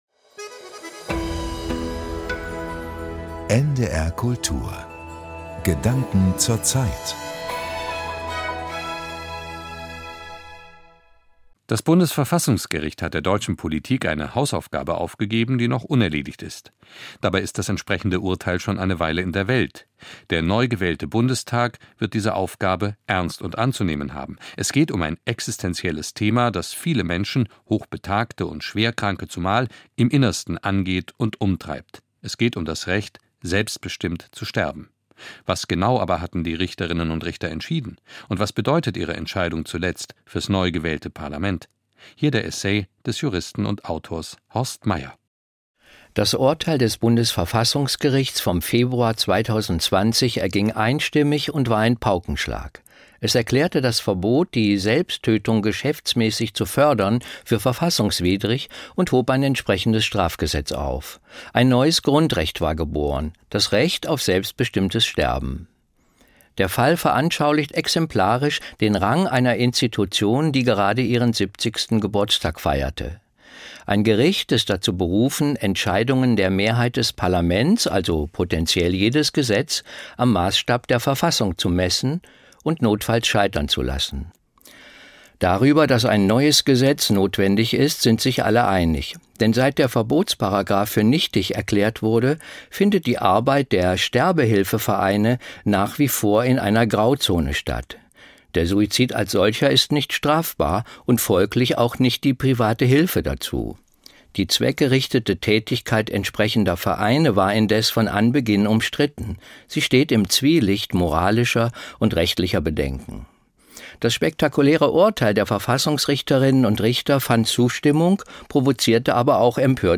Essay Gedanken zur Zeit, NDR Kultur, 16.